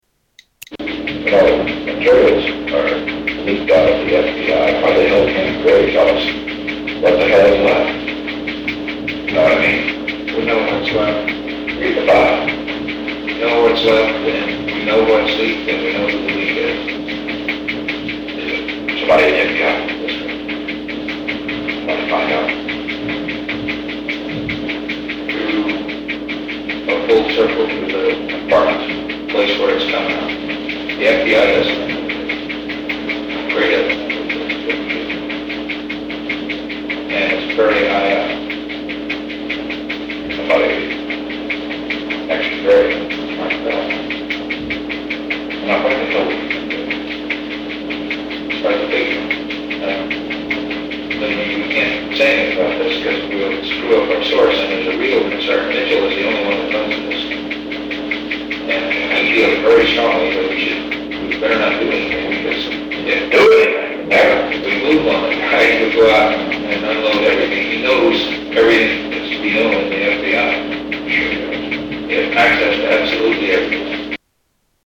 Tags: White House tapes Presidents Secret recordings Nixon tapes White house